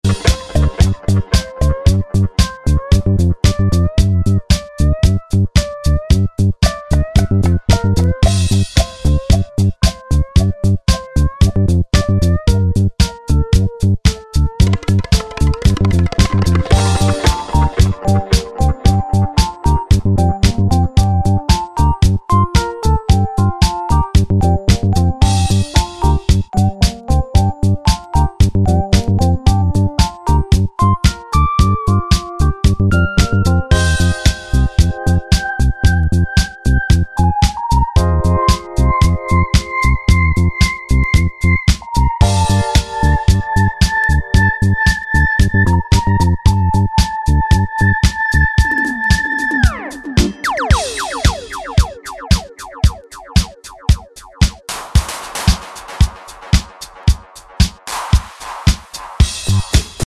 warm and grooving disco-isch electro/house/techno tracks
Disco House